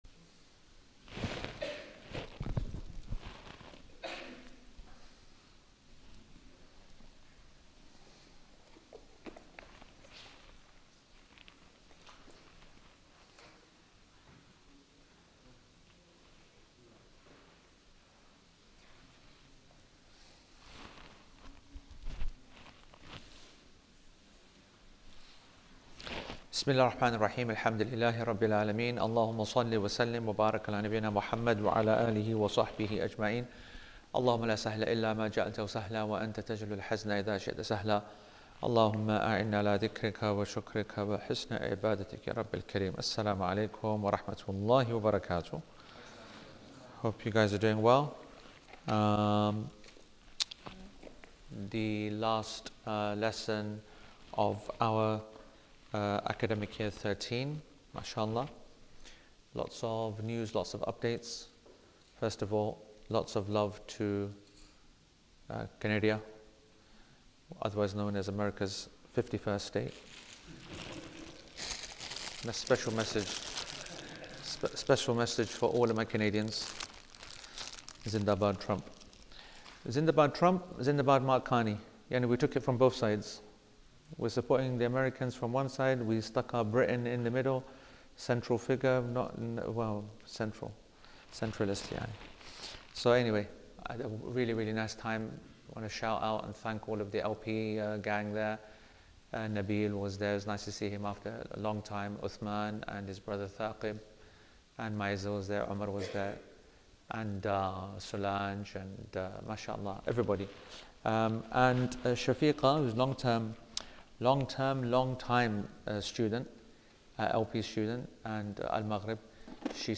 Play Rate Listened List Bookmark Get this podcast via API From The Podcast A weekly class on Islamic foundational principles, theology, law and ethics based on al-Sharḥ’l-Mumti’ ‘alā Zād’l-Mustaqni’.